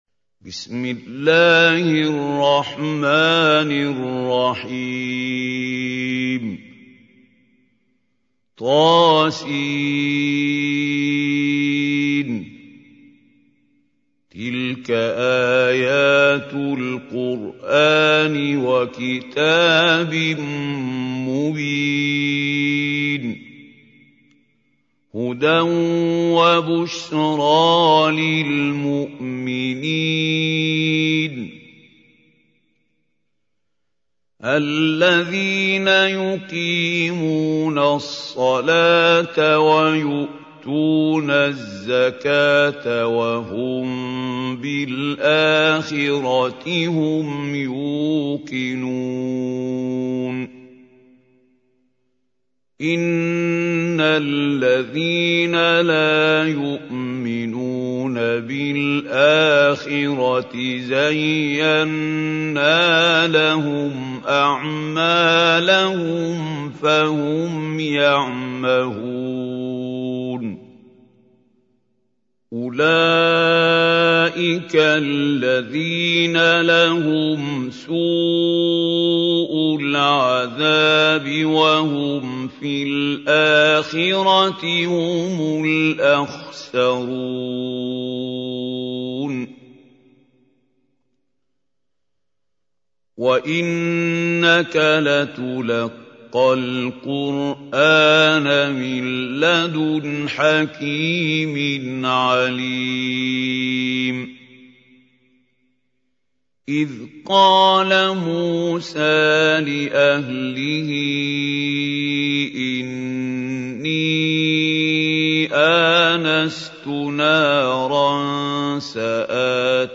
Чтение Корана > ХАЛИЛ ХУСАРИ